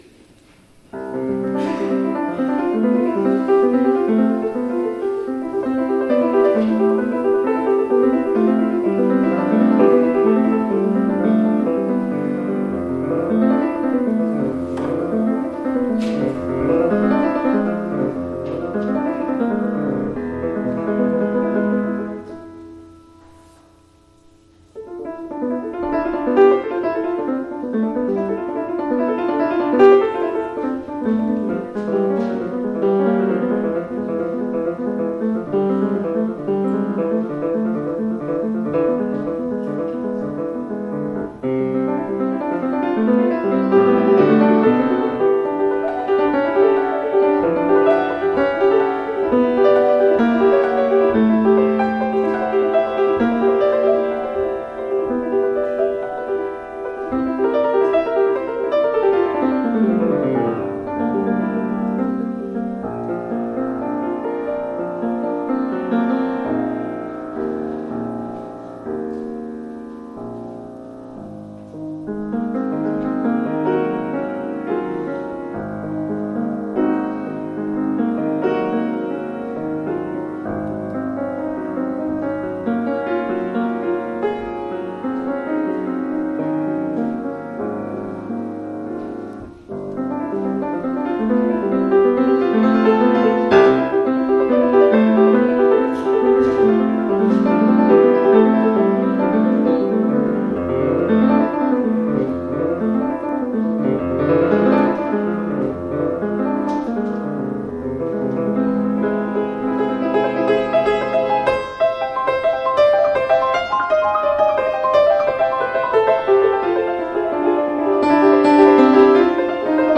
PRELUDE -  from: Children's Corner Suite - Dr. Gradus ad Parnassum   -
In this first movement of six, Debussy satirizes piano